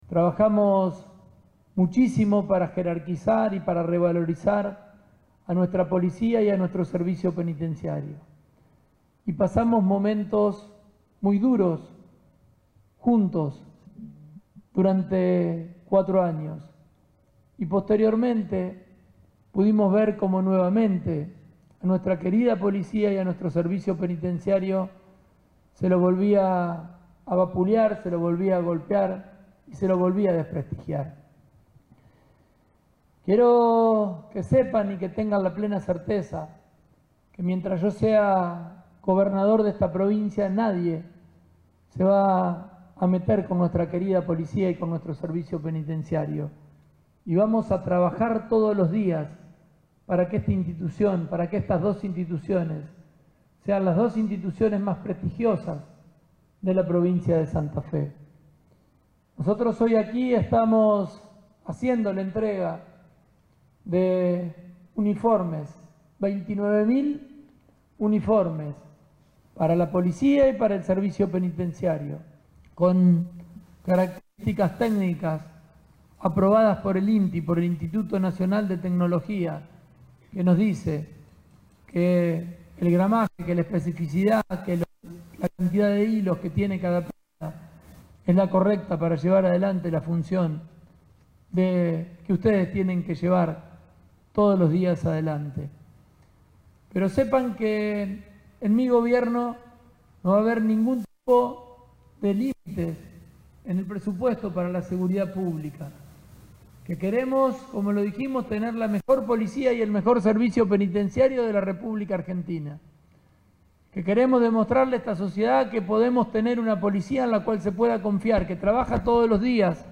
Durante el acto en la sede del Instituto de Seguridad Pública (ISEP) en la ciudad de Rosario, Pullaro indicó que “tuvimos que trabajar mucho para que la sociedad pueda comprender la importancia de las fuerzas de seguridad pública, jerarquizar y revalorizar a nuestra policía y servicio penitenciario”.